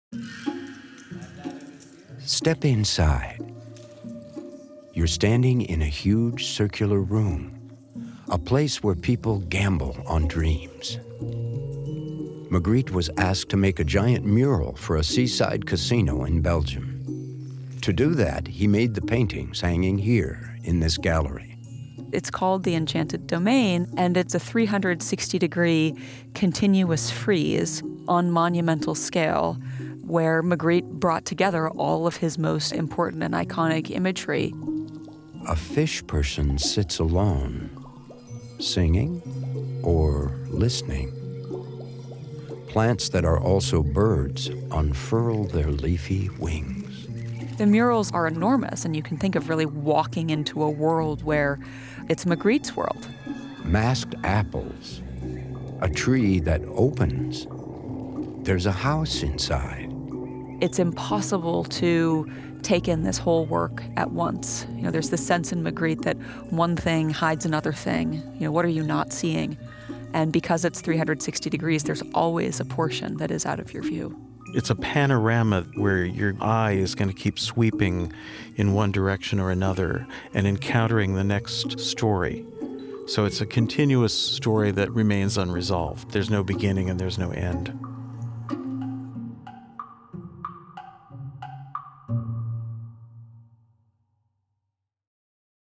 from a San Francisco Museum of Modern Art show on the painter Magritte (This audio clip is encoded in Binaural and requires headphones)